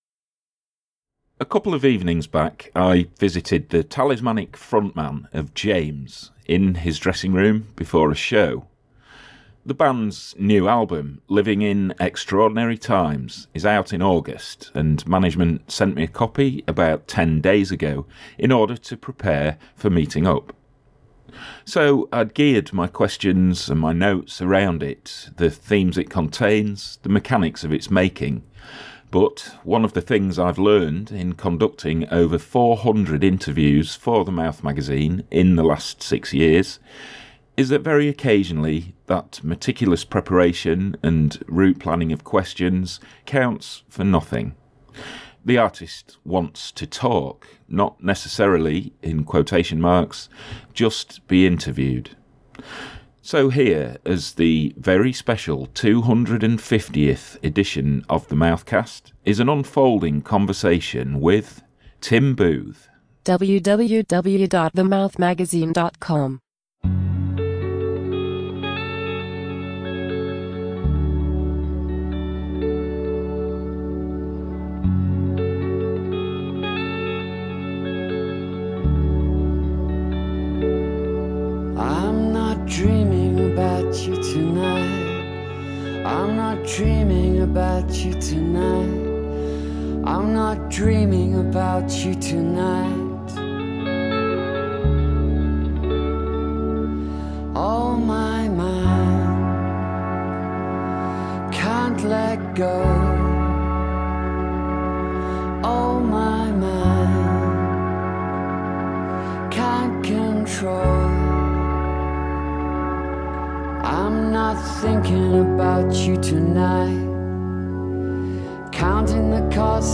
IN THIS VERY SPECIAL TWO HUNDRED AND FIFTIETH EDITION OF THE MOUTHCAST, RECORDED BACKSTAGE PRIOR TO A RECENT SHOW BY ENDURING MANCHESTER BAND JAMES, TALISMANIC FRONTMAN TIM BOOTH OPENS UP AND DISCUSSES HIS LIFE AND BELIEFS…